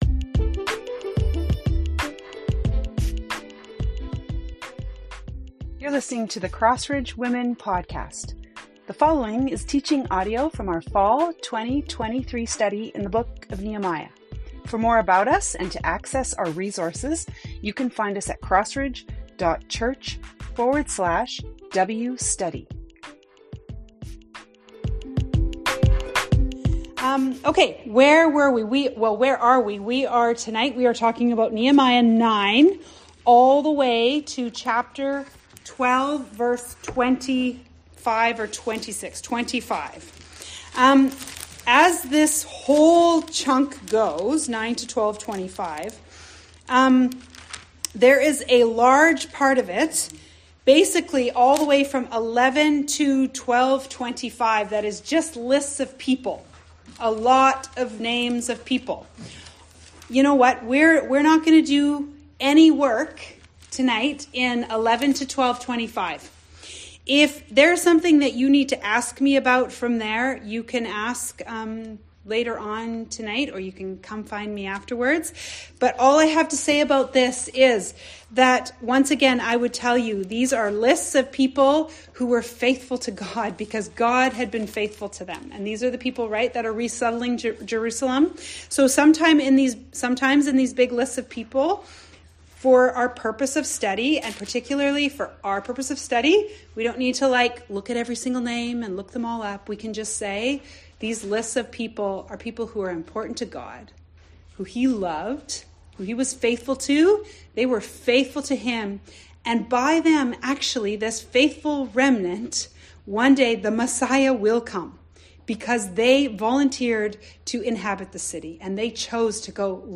Audio from our Large Group discussion and Teaching time.